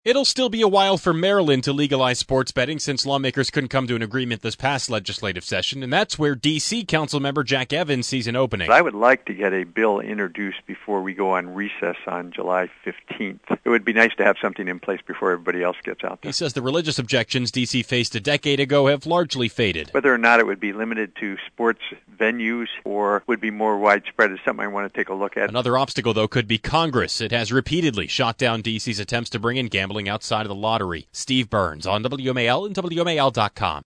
“Sports betting is going to be something that’s very popular, so I really have to look nationwide at how other jurisdictions sre doing it, and then move forward in the District of Columbia,” Evans said in an interview.